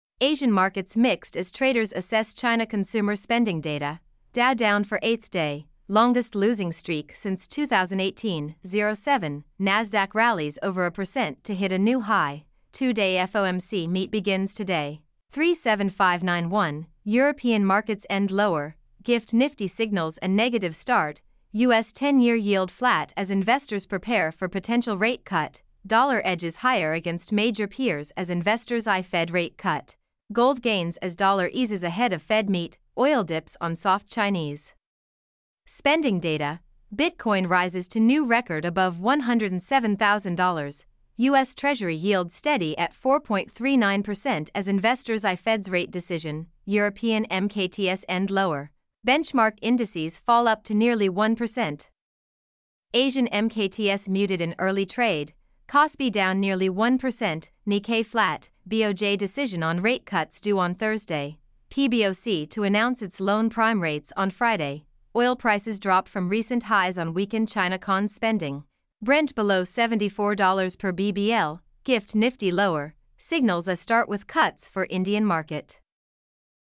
mp3-output-ttsfreedotcom-35.mp3